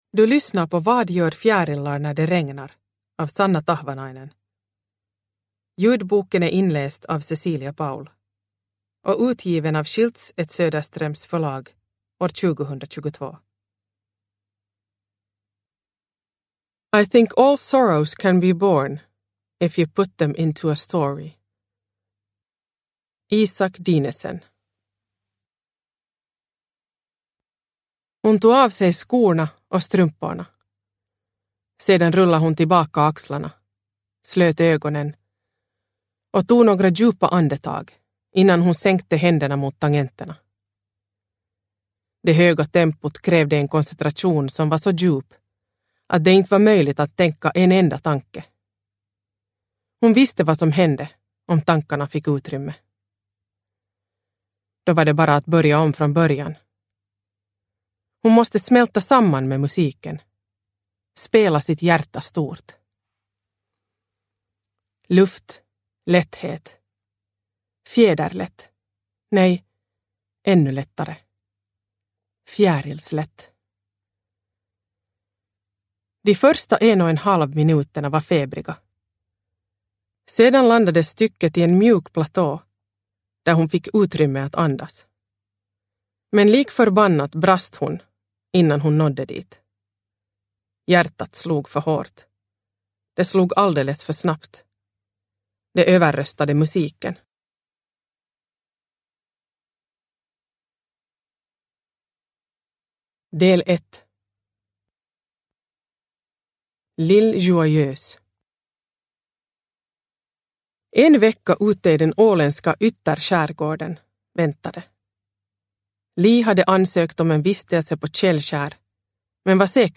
Vad gör fjärilar när det regnar? – Ljudbok – Laddas ner